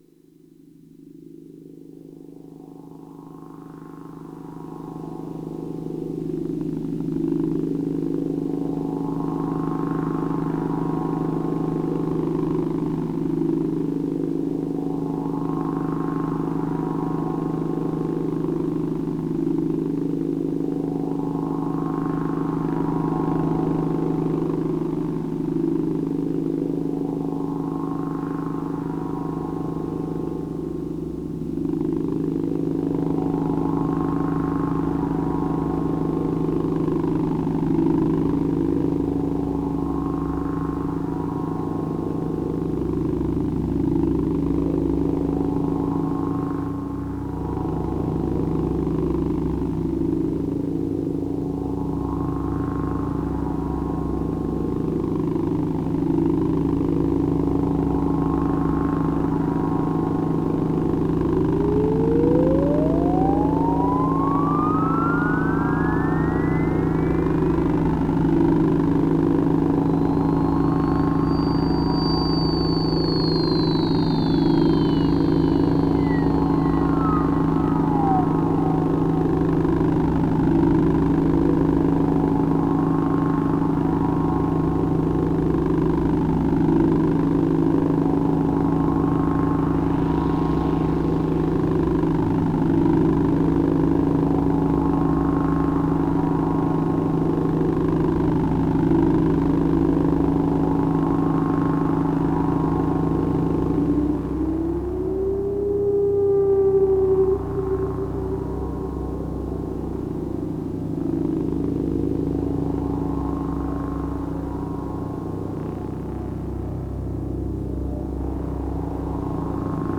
Improvisé à partir de sons préexistants.
Instrumentarium A100 Model-D au ruban Raspberry (Echoid) Écouter Existasia Année : 2025 Durée : 4'40 Format : ogg (stéréo) Masse : 39,2 Mo Projet : Le monde des dieux